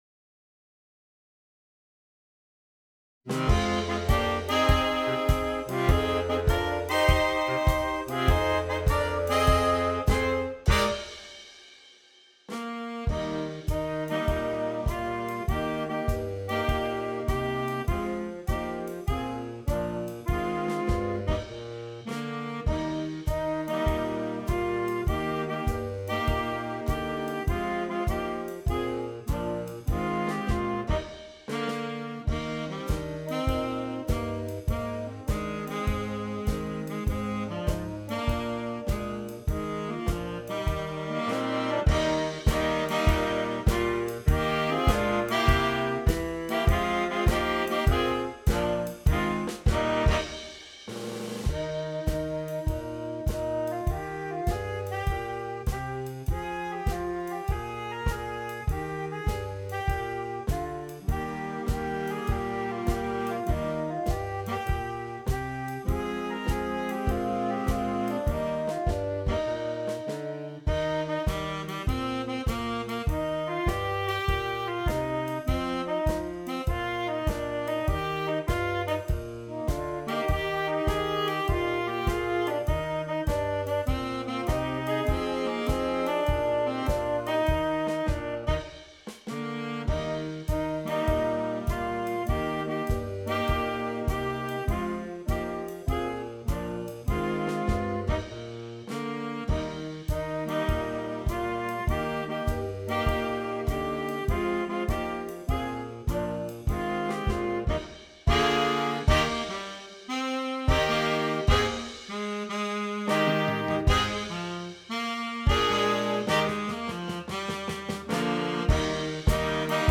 Optional Drum Set part is included.